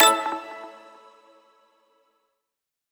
select-2.wav